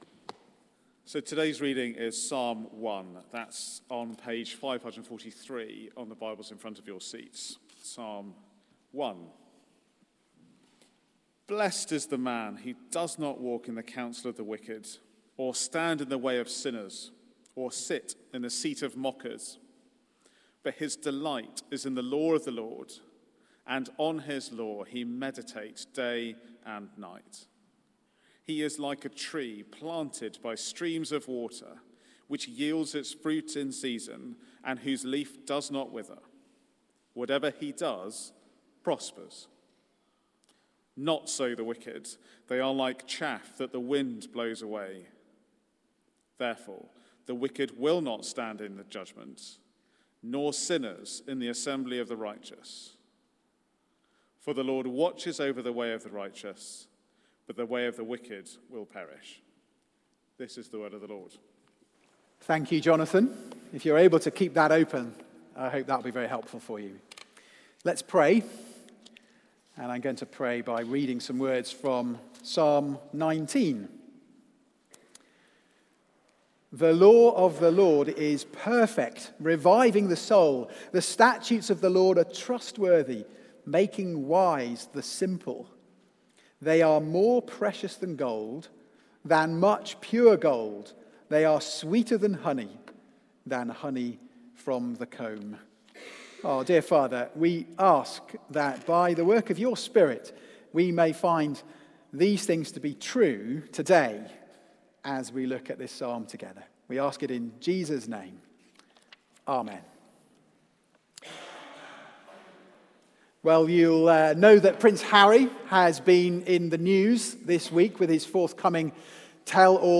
Media for Service (10.45) on Sun 08th Jan 2023 10:45
Theme: The One Way to Blessing Sermon